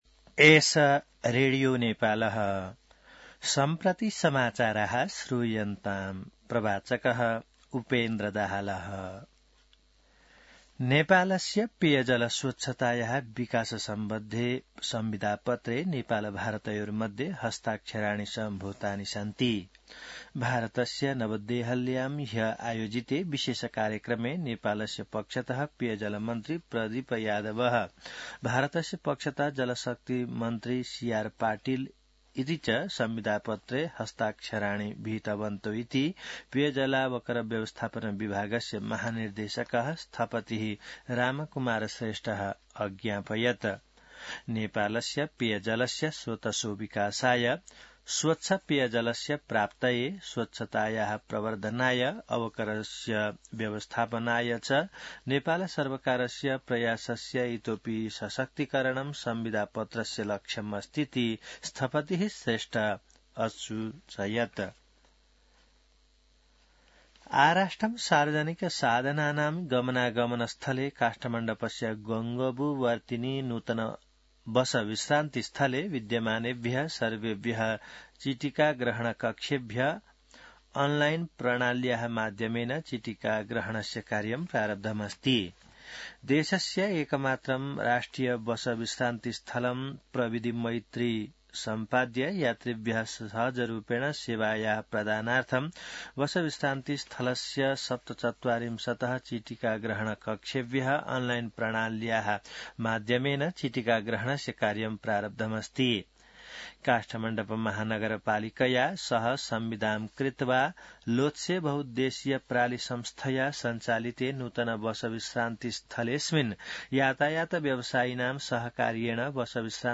संस्कृत समाचार : २१ फागुन , २०८१